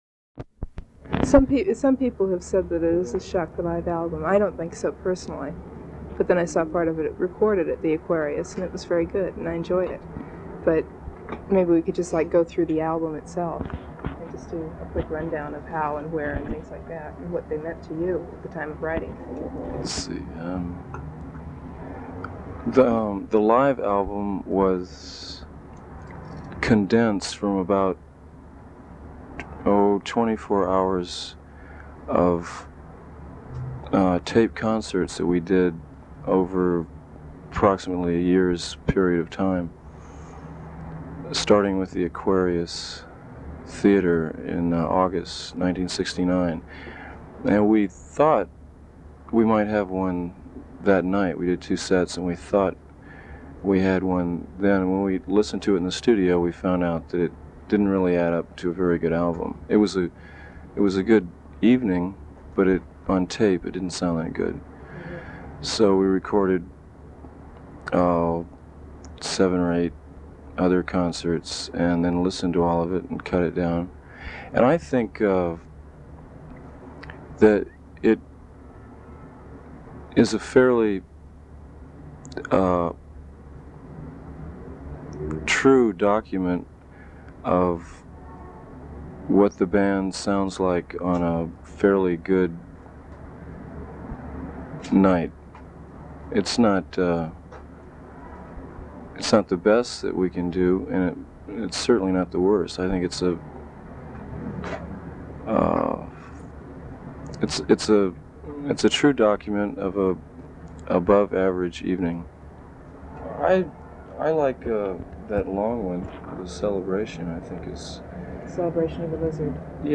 The Doors/The Lost Interview Tapes Featuring Jim Morrison - Volume Two The Circus Magazine Interview (Album)
01 About Absolutely Live (Interview Segment).flac